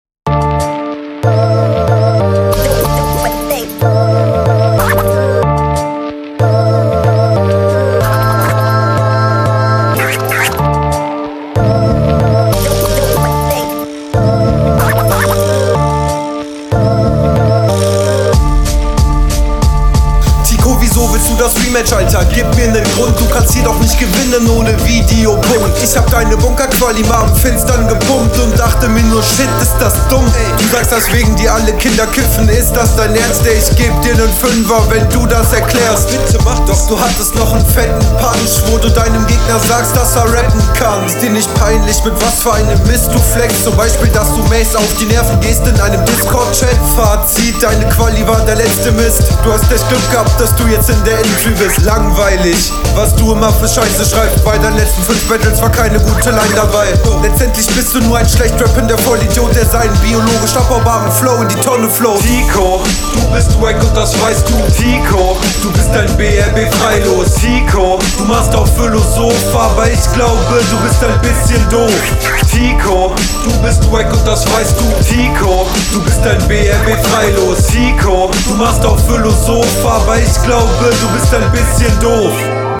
Soundtechnisch leider eine Verschlechterung zu RR1.
ja sehr cooler Beat. passt viel besser zu dir (stimmlich) auch wenn du stimmlich noch …